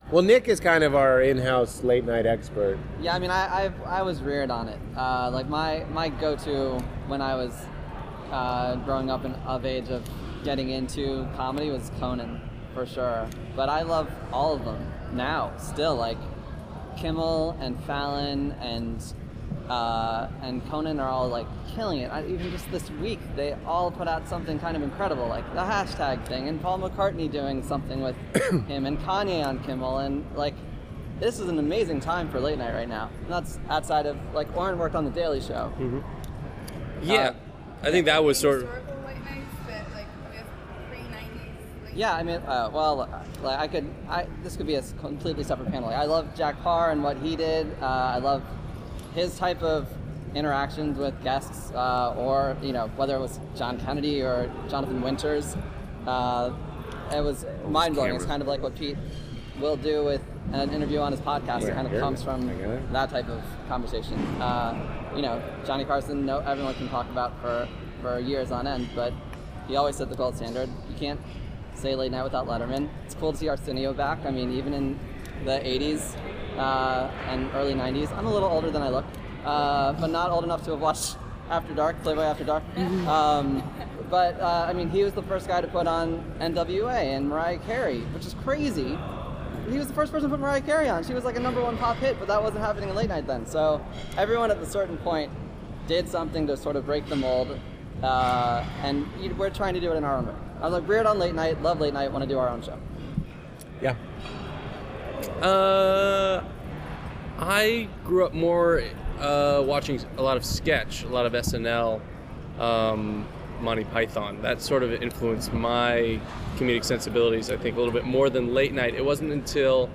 While audio of the Q&A was recorded, most of the questions coming in were too far from the microphone to be heard well, so you can read each question below and then hear the response given by the panel.